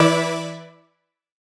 Index of /musicradar/future-rave-samples/Poly Chord Hits/Straight
FR_SOBX[hit]-E.wav